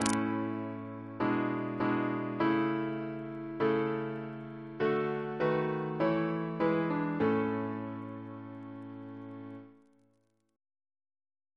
Single chant in A Composer: Anonymous Reference psalters: ACB: 148; PP/SNCB: 178; RSCM: 164